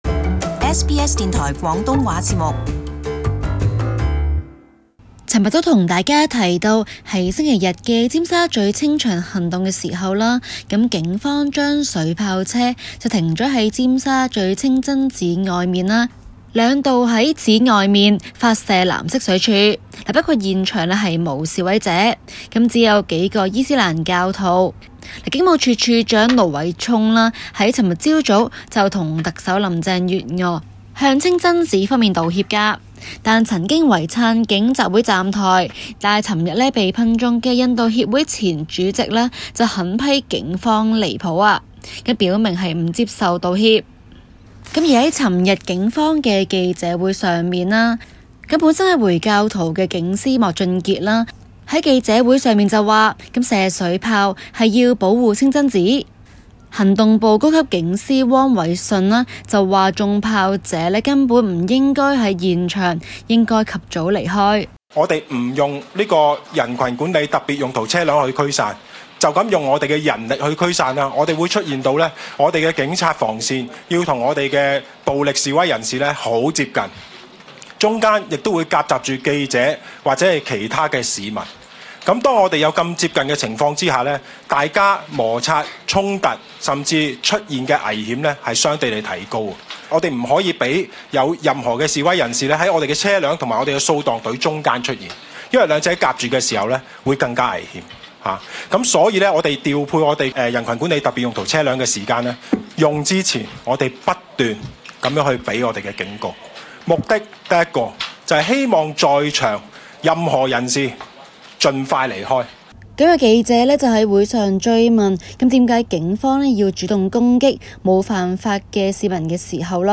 Source: AFP SBS廣東話節目 View Podcast Series Follow and Subscribe Apple Podcasts YouTube Spotify Download (10.09MB) Download the SBS Audio app Available on iOS and Android 香港警方的水炮車，前日向位於尖沙咀的九龍清真寺及門外的市民，兩度發射藍色水炮。